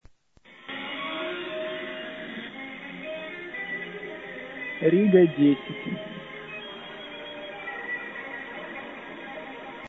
Слабый сигнал на "Риге"